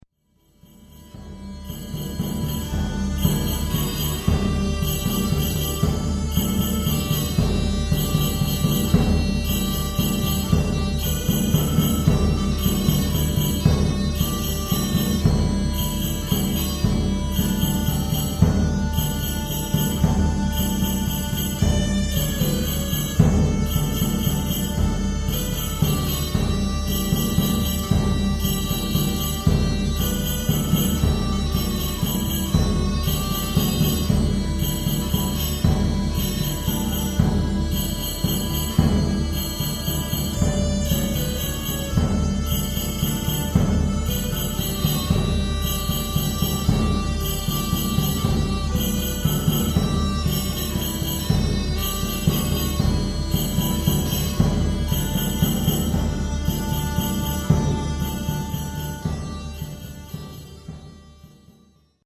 Danse Real – Anon. A processional dance from the 13th century Chansonnier du Roi. We play it on two types of bagpipe with percussion, and sometimes use it to process in to the venue at the beginning of our concerts.